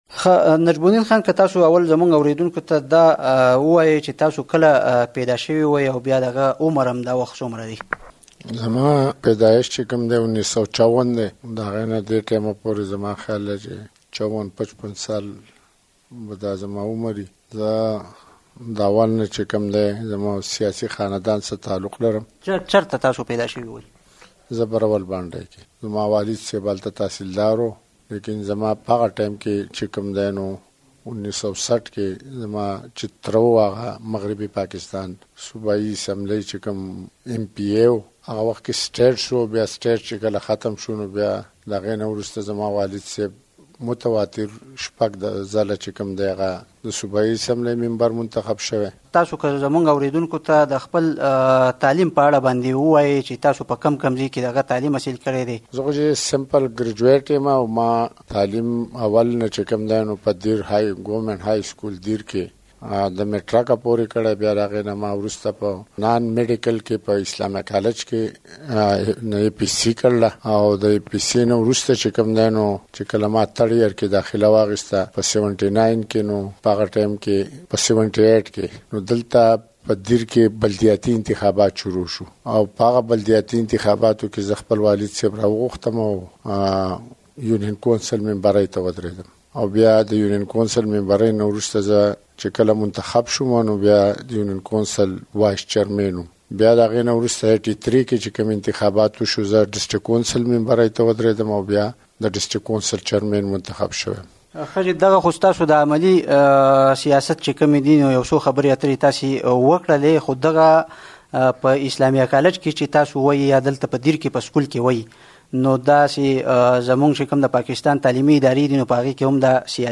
د بره دیر پارلماني غړی نجم الدین خان سره مرکه لرو